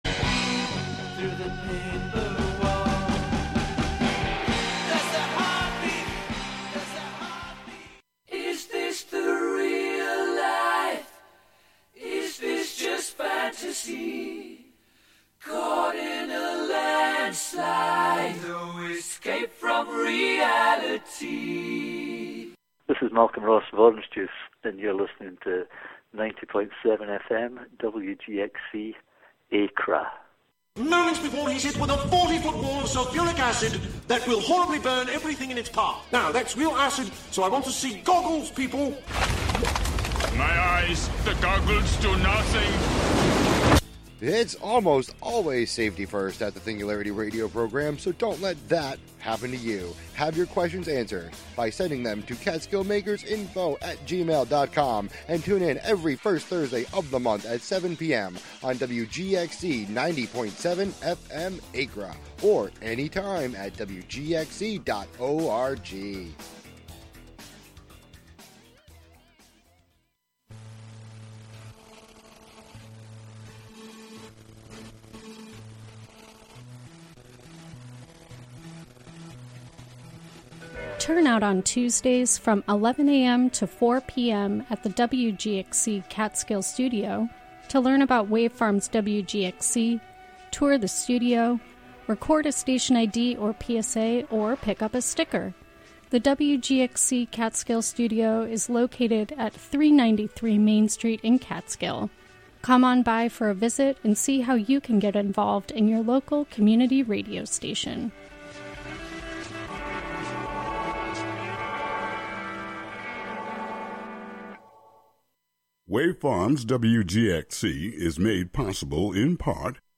just the fictions read for the listener as best as i can read them.